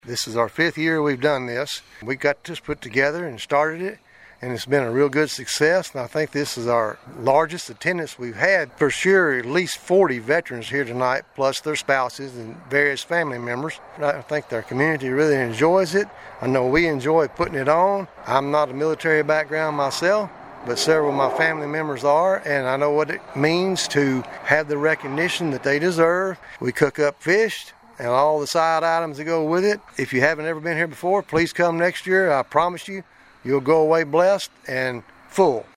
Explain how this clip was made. The Pleasant Grove Baptist Church located on Hopkinsville Road in Princeton recognized Veterans on Saturday night with a fish meal prepared by the church members.